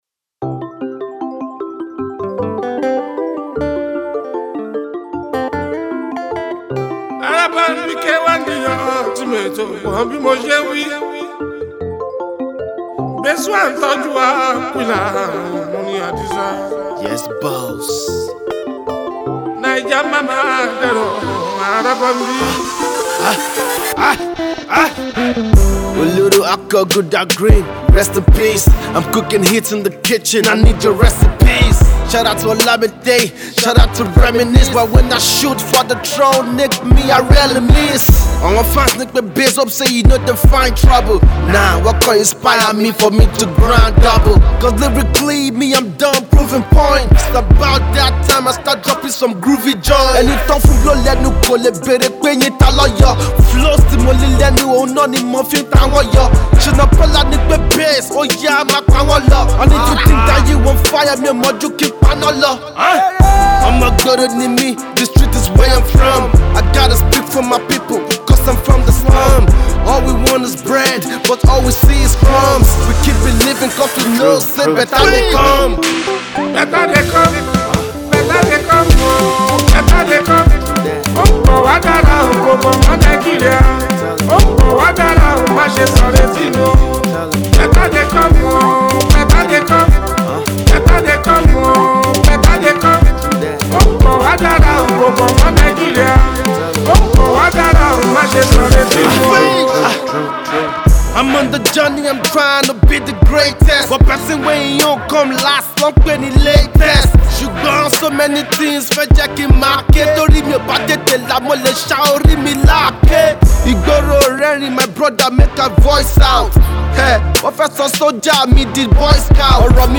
rapper
Fuji